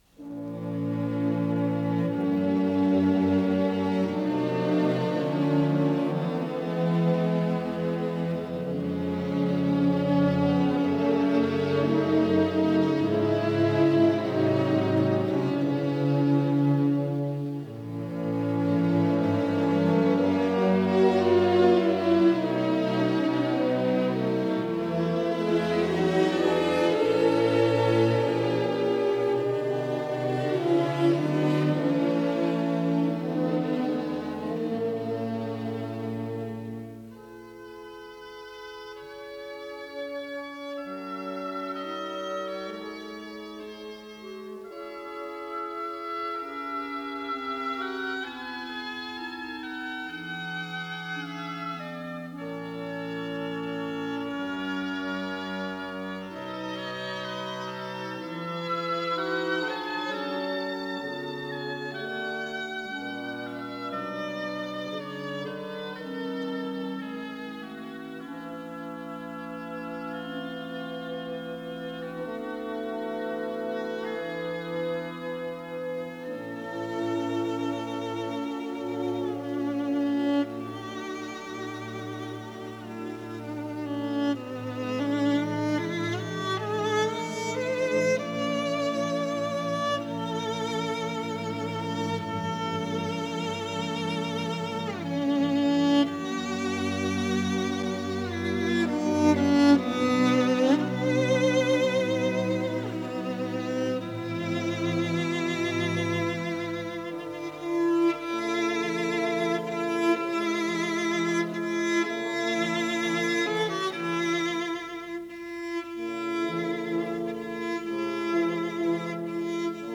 Исполнитель: Виктор Пикайзен - скрипка
Соч. 14, фа диез минор